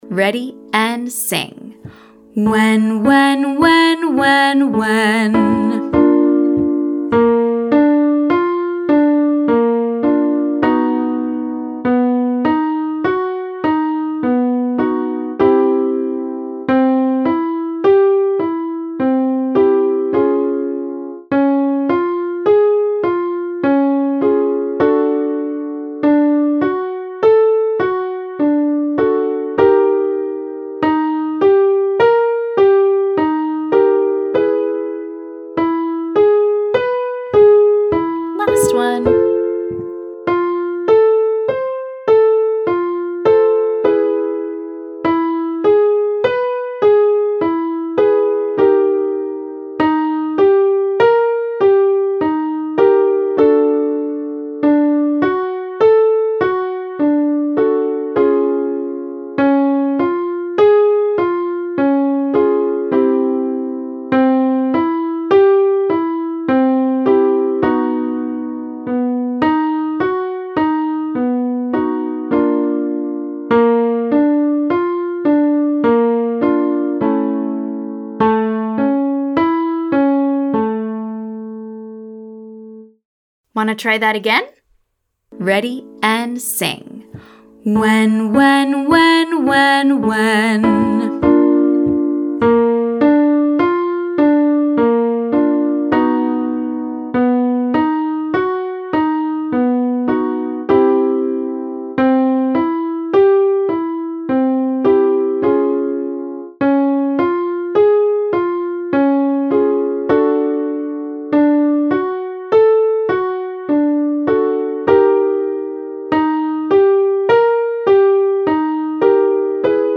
Our exercises today use this slightly wider shape on the word WHEN or WEH .